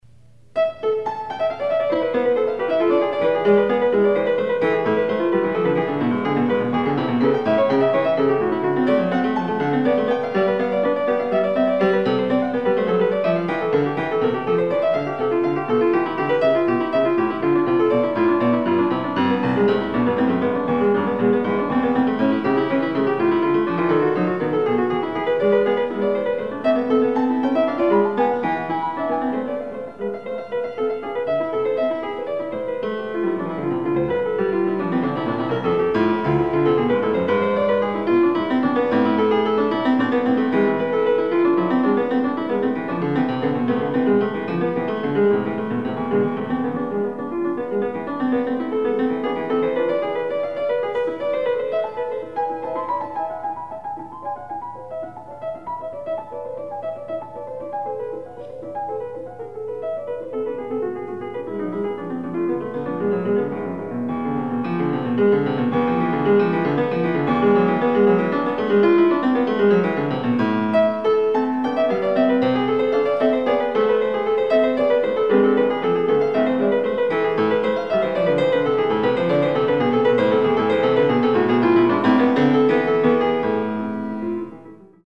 Additional Date(s)Recorded September 15, 1977 in the Ed Landreth Hall, Texas Christian University, Fort Worth, Texas
Short audio samples from performance